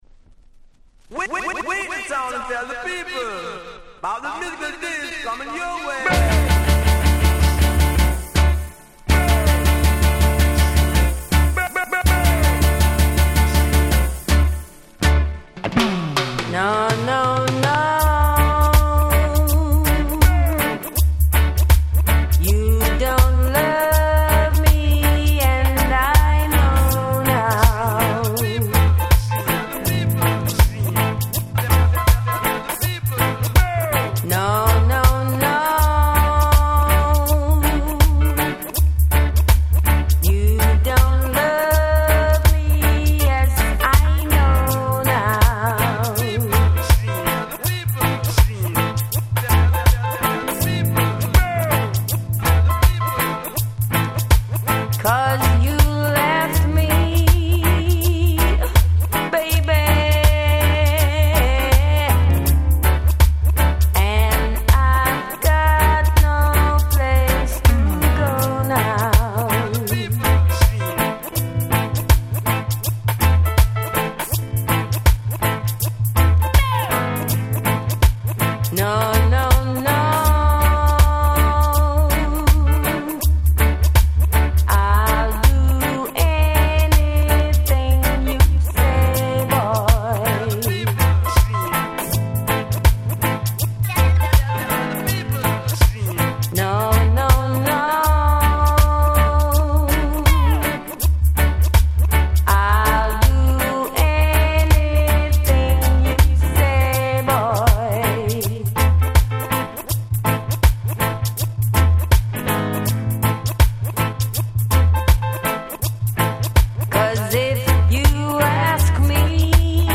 問答無用のReggae Classic !!
サビの『のーのーのぉ〜♪』のフレーズはあまりにも有名。